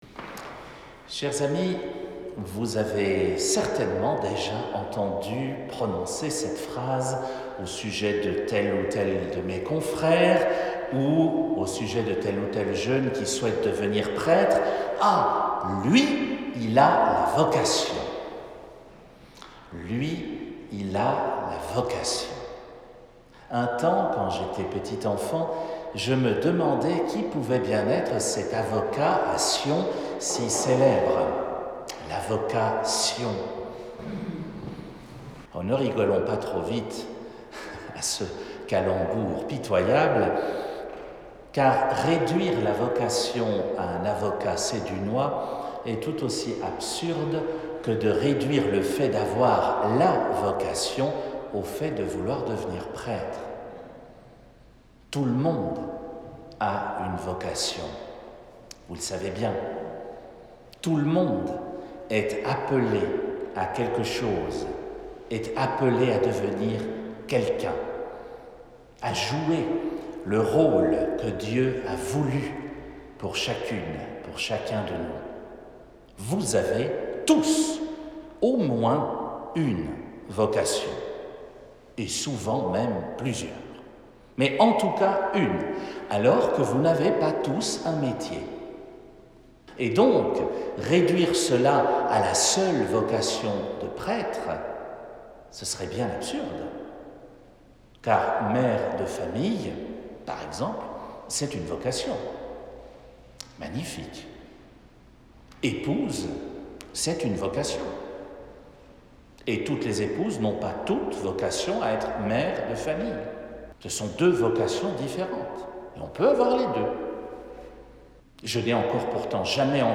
Homélie pour le 2e dimanche TO, année B